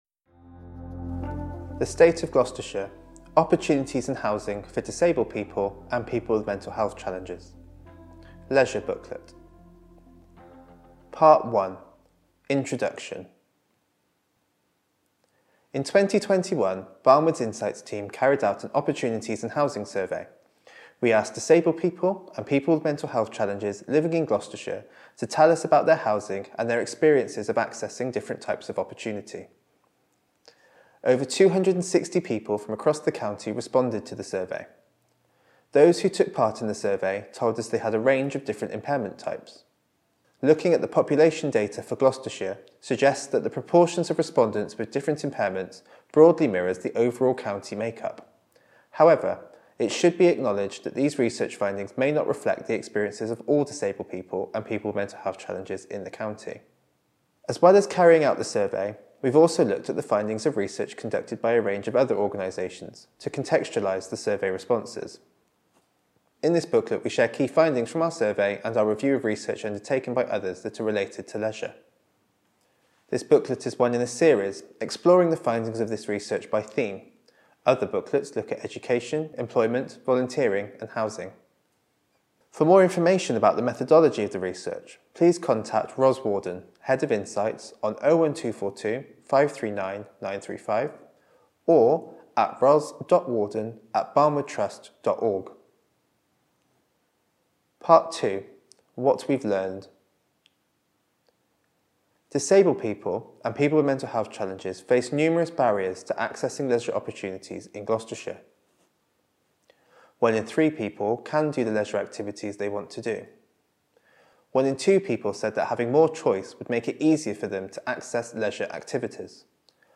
British Sign Language (BSL) translation Subtitled video of the report being read by one of our Researchers Audio recording of the report being read by one of our Researchers Easy Read version to read or download Large print version to read or download